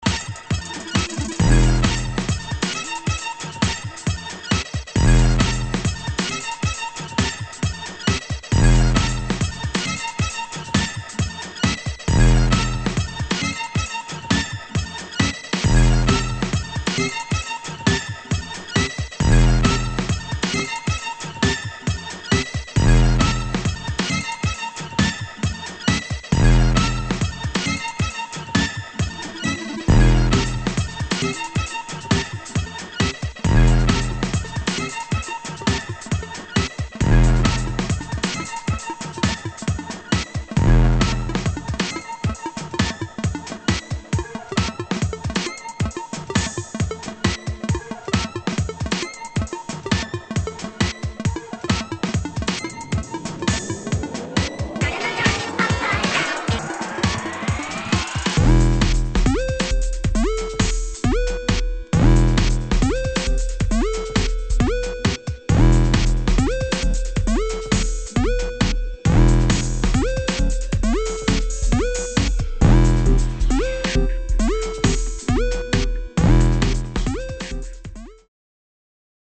[ DUBSTEP / UK GARAGE ]